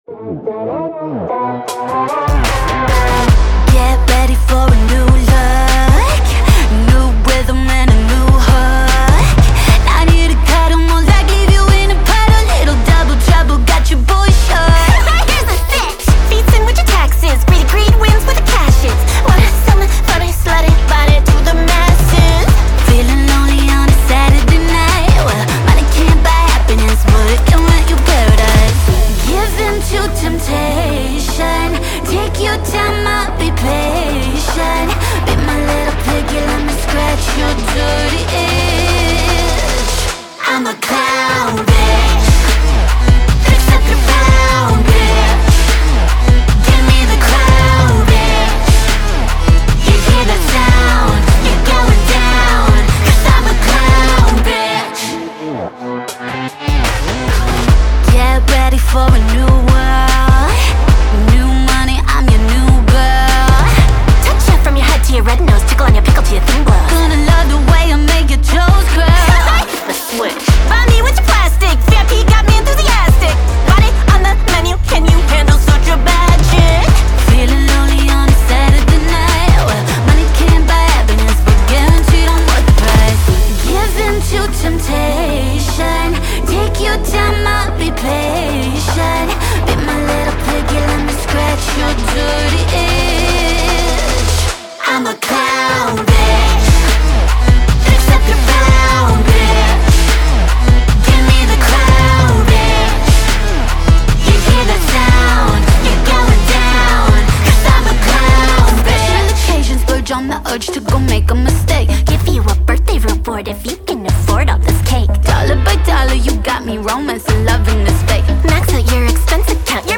BPM150-150
Audio QualityPerfect (High Quality)
Pop Trap song for StepMania, ITGmania, Project Outfox
Full Length Song (not arcade length cut)